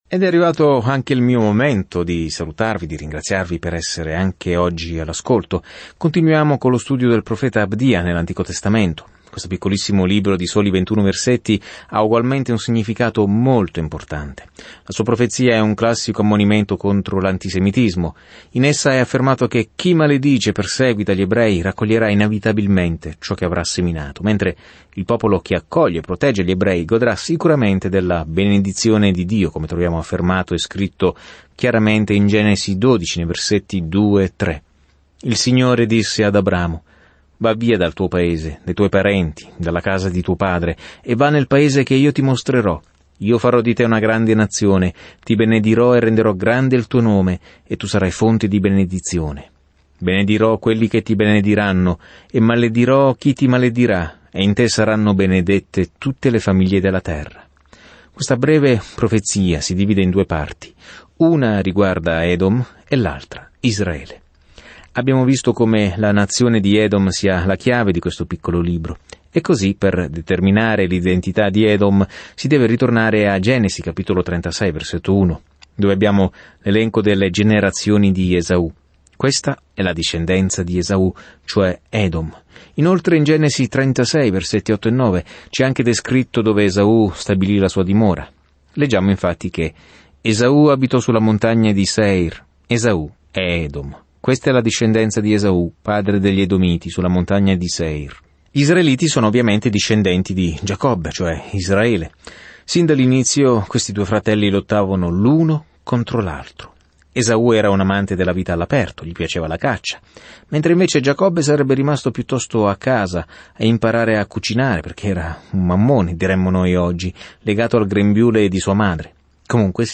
Scrittura Abdia 1:13-15 Giorno 3 Inizia questo Piano Giorno 5 Riguardo questo Piano Un cervo orgoglioso è più profondo e più oscuro di qualsiasi altro peccato perché dice che non abbiamo bisogno di Dio: è così che Abdia mette in guardia le nazioni confinanti con Israele. Viaggia ogni giorno attraverso Abdia mentre ascolti lo studio audio e leggi versetti selezionati della parola di Dio.